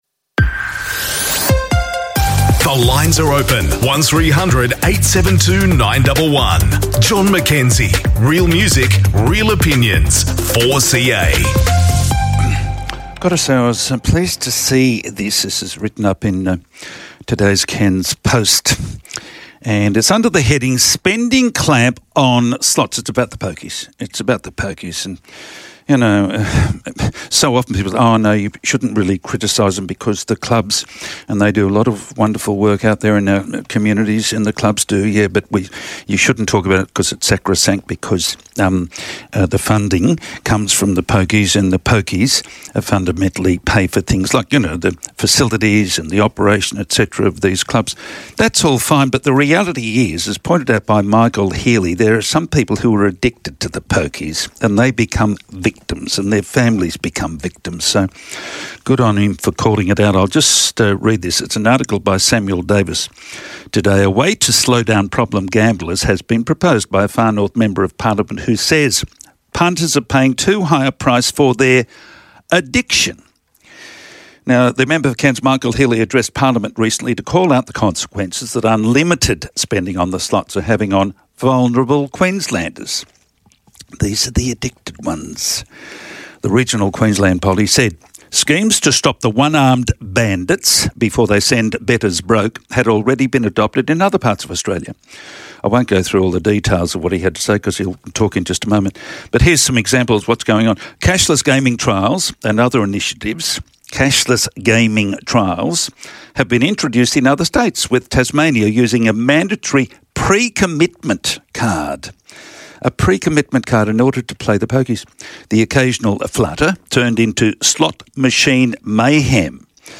speaks with Michael Healy, ALP State Member for Cairns, about legal reform and proposed restrictions for poker machine users.